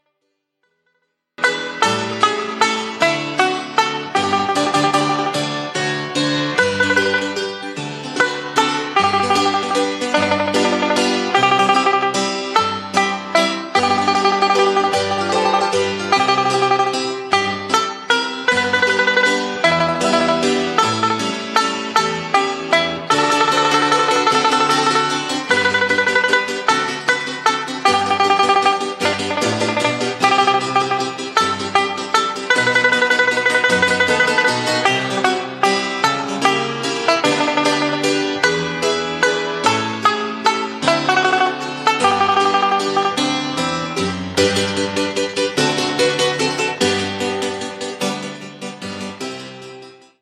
12-beat intro.
This song is in 34 waltz time.